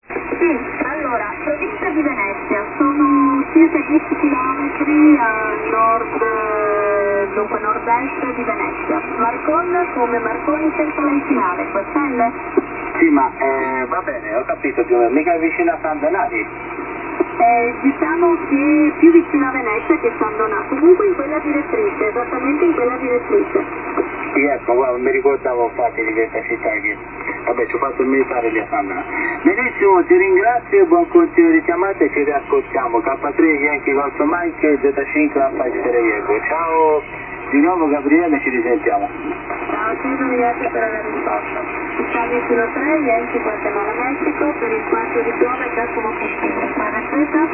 ESCUCHA A LOS RADIOAFICIONADOS 2